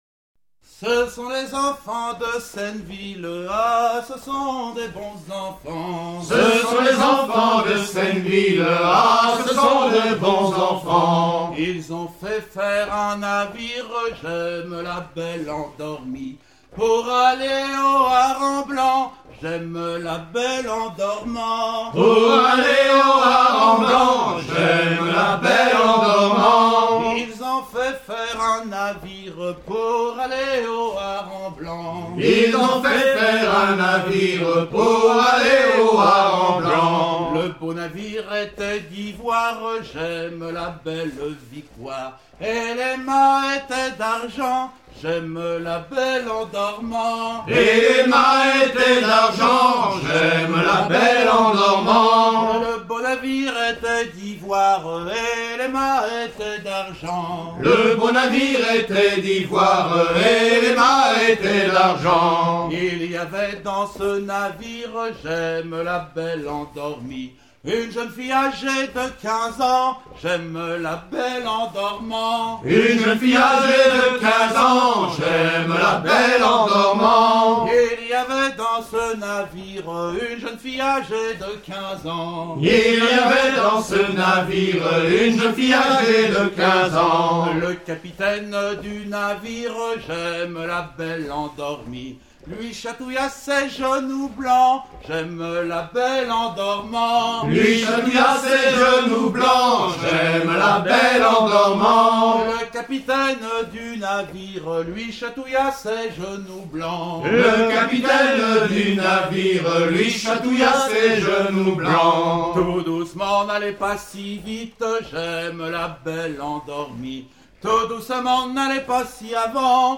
danse : ronde à trois pas
Pièce musicale éditée